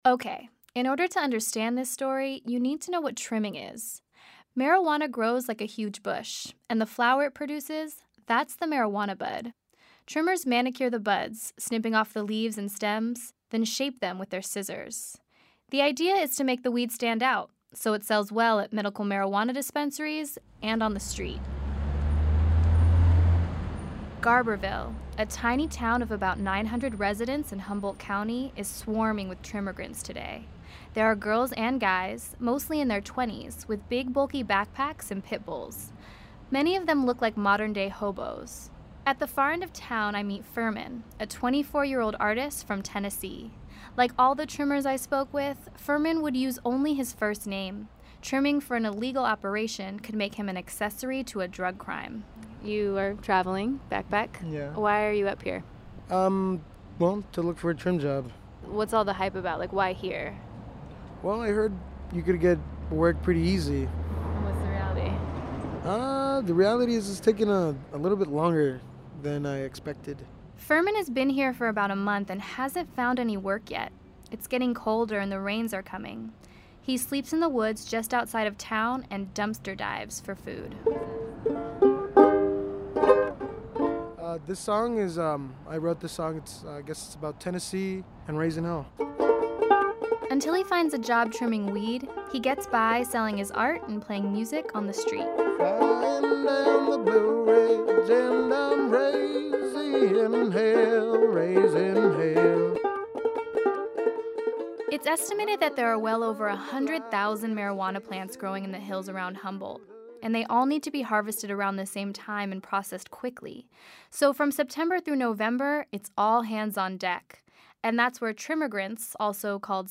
Music is a big part of trimming, and the faster the music, the faster they snip. I watched as their scissors sync with the beat.